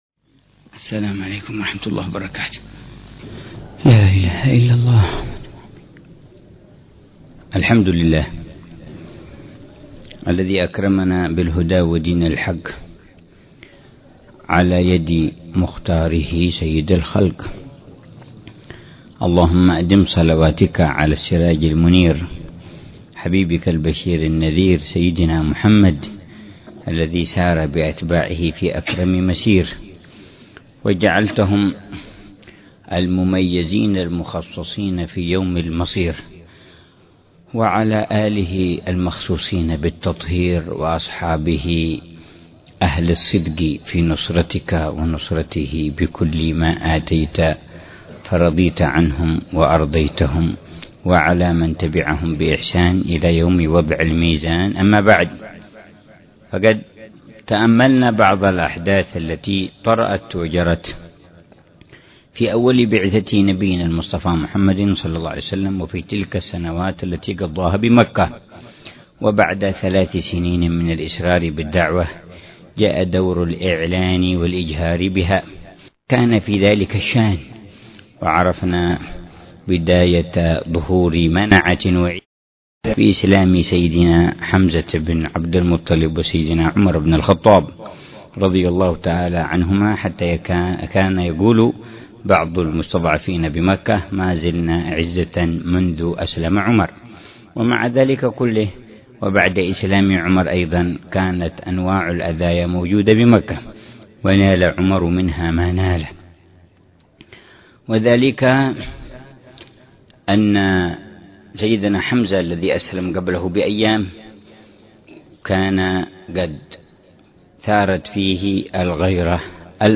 الدرس العاشر من دروس السيرة النبوية ( جولان الروح في سيرة رسول الله باب الفتوح ) التي يلقيها الحبيب/ عمر بن محمد بن سالم بن حفيظ لطلاب الدورات